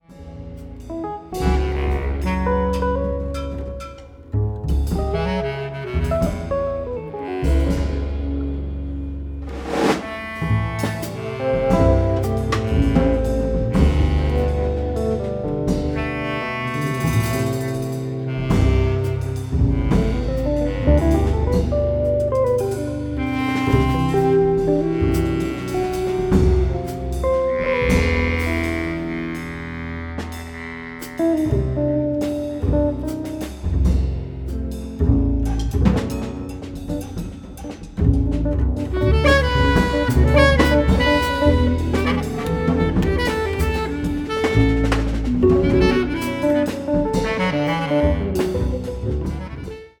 相変わらずクールで知的なサウンドが展開、リラックスしたムードの中気楽に楽しめる内容となっています。
clarinet, bass clarinet, alto saxophone
electric guitar
Fender Rhodes, piano, synths
drums
acoustic and electric bass